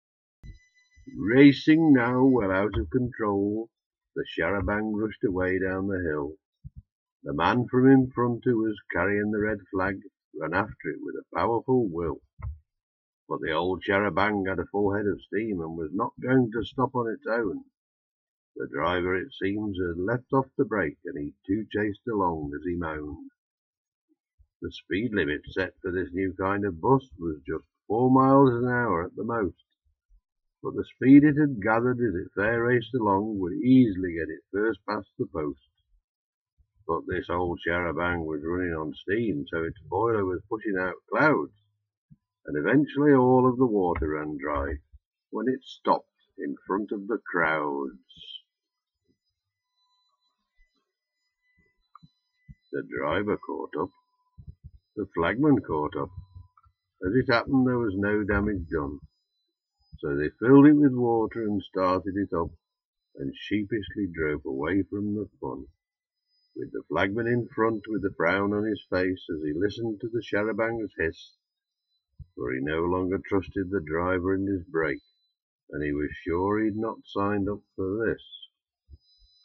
If you read this aloud with as broad a Lancashire accent as you can manage you’ll get the idea I’m conveying.